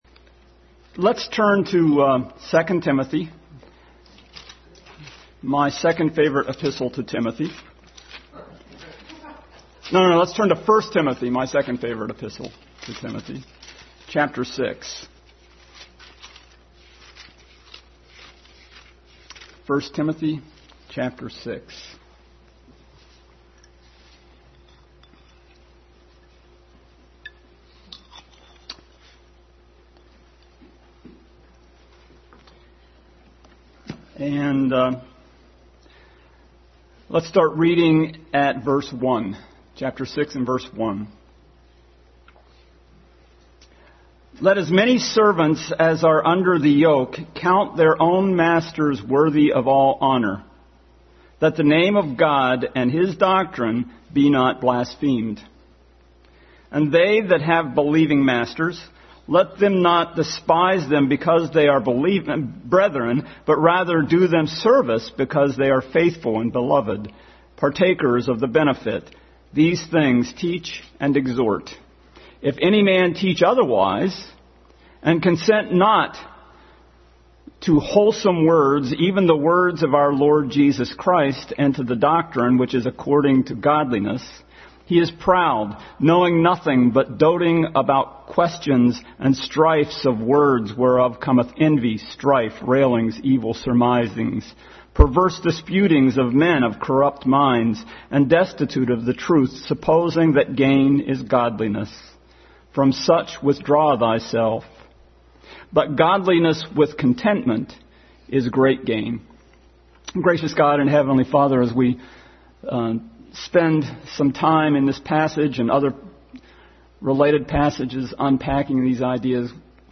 Adult Sunday School continued study in 1 Timothy.
Philippians 2:5-11 Service Type: Sunday School Adult Sunday School continued study in 1 Timothy.